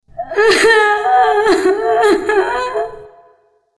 witch_llora_1.wav